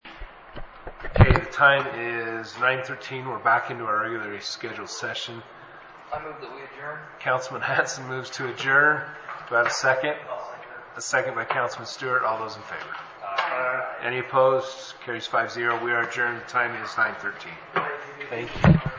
City Council Meeting